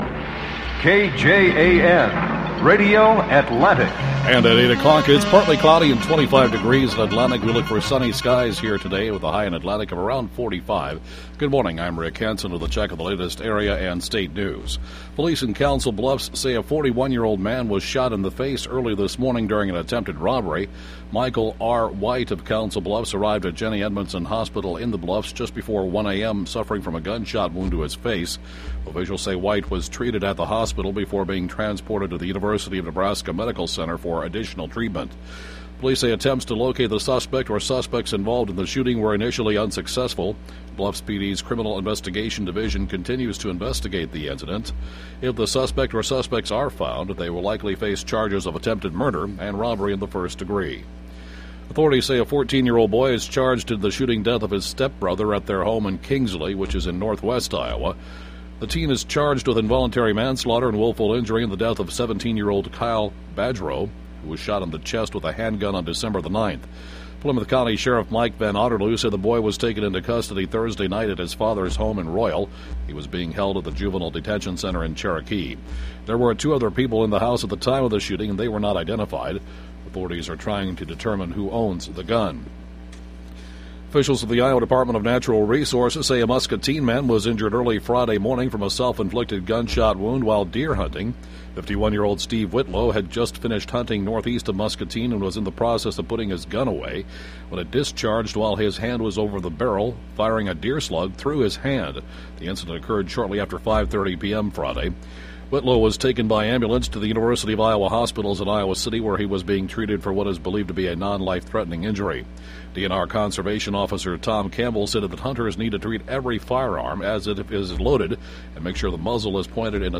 Saturday 8AM News (podcast) 12-17-11